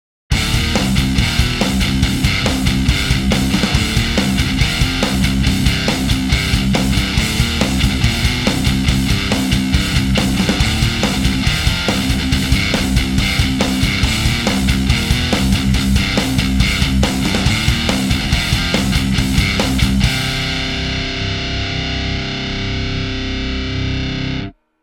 METAL Dime 1.mp3